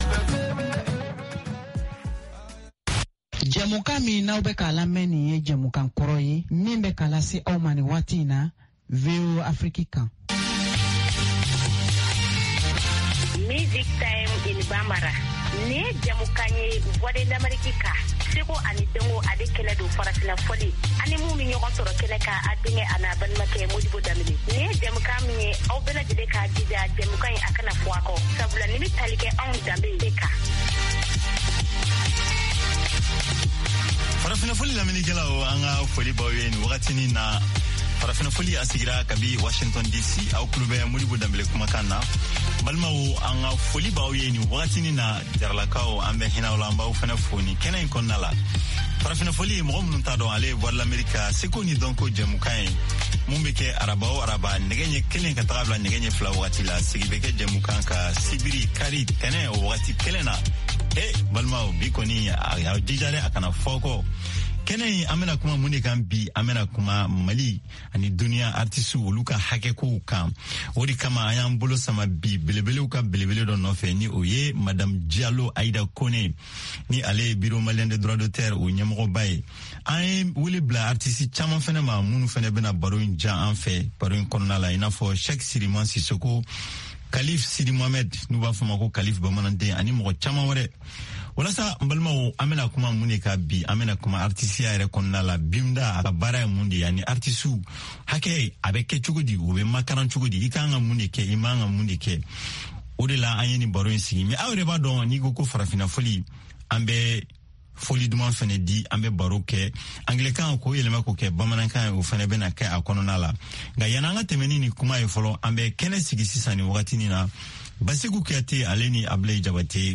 Bulletin d’information de 17 heures
Bienvenu dans ce bulletin d’information de VOA Afrique.